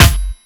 dde snare 9.wav